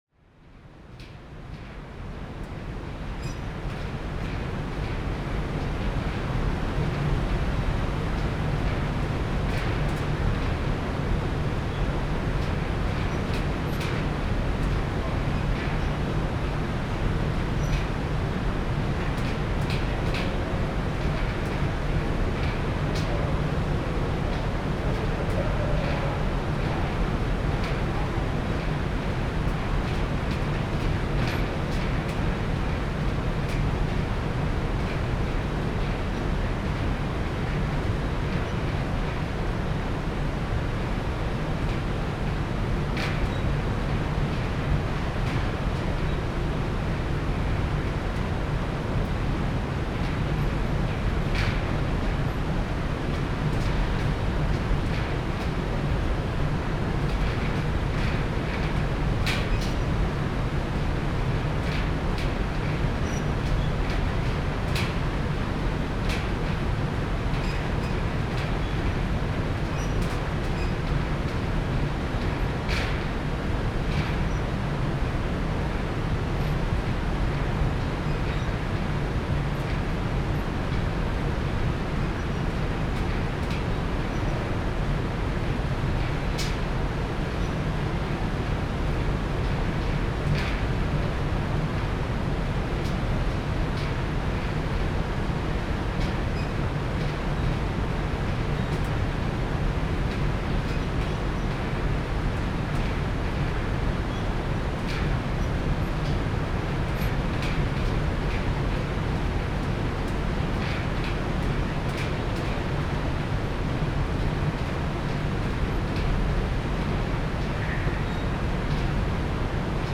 AMB_Scene04_Ambience_L.ogg